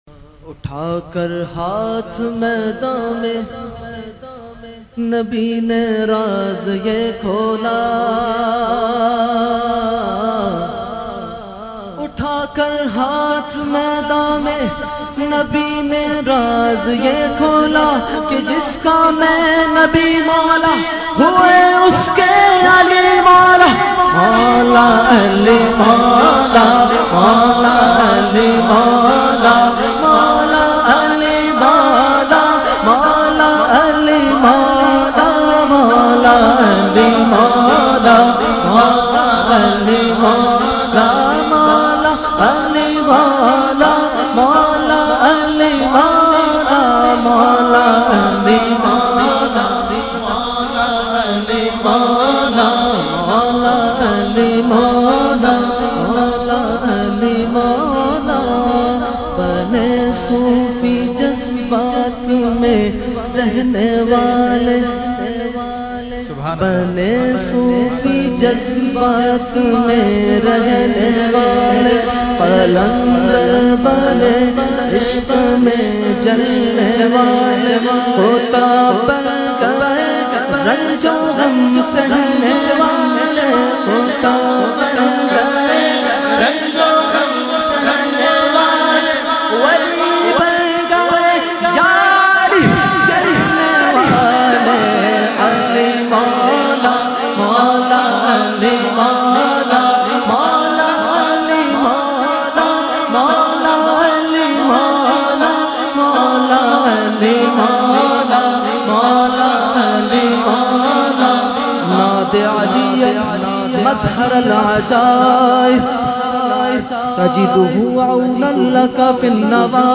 Category : Manqabat | Language : UrduEvent : Muharram 2020